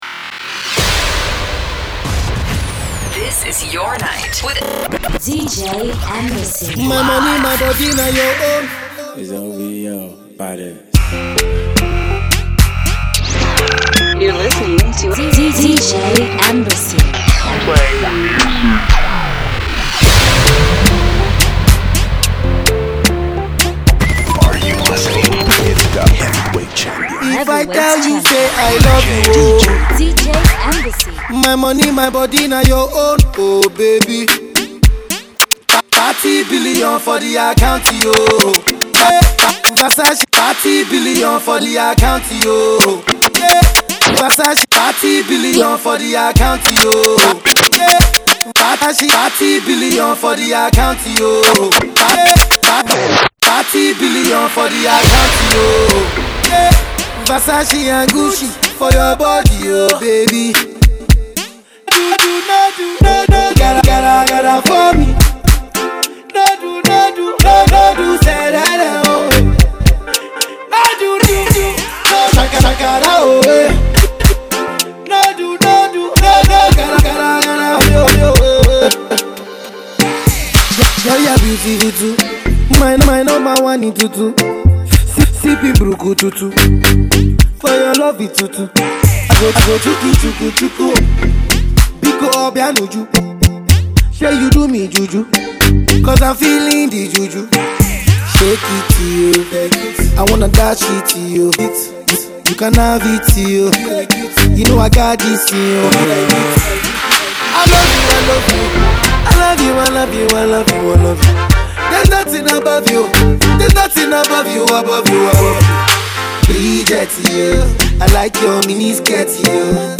banging mix tape